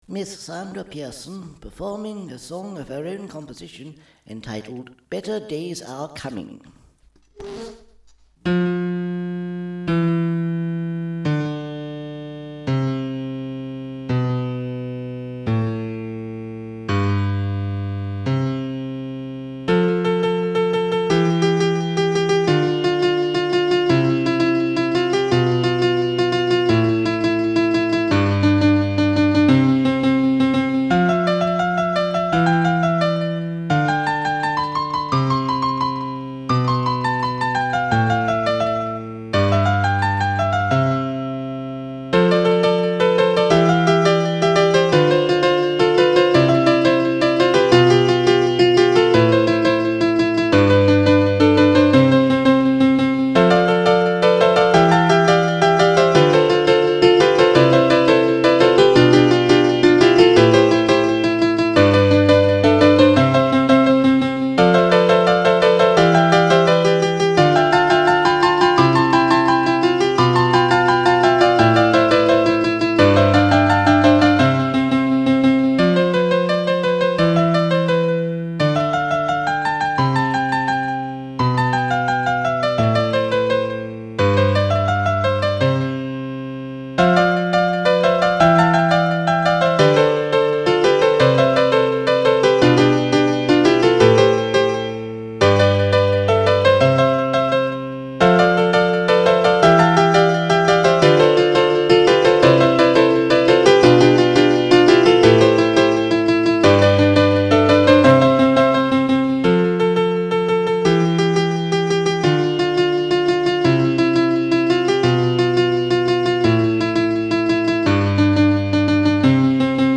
Description: A bright little piano number
Genre: jazzy classical
It got me first place in the talent contest.
The announcer, by the way, got the name wrong.